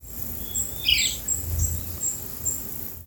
Scalloped Woodcreeper (Lepidocolaptes falcinellus)
Province / Department: Misiones
Detailed location: Misión de Loreto
Condition: Wild
Certainty: Recorded vocal